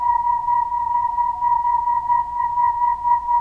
Percussion
alien3_f.wav